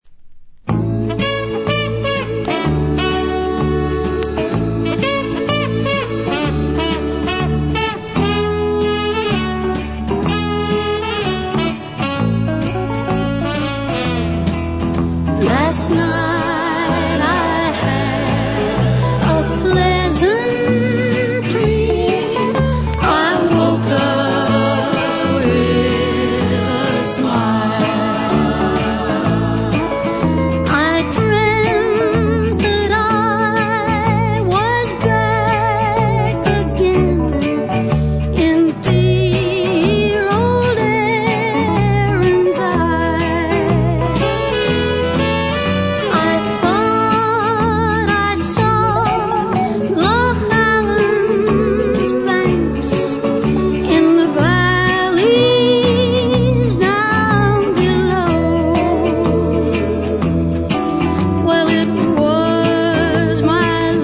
(vinyl)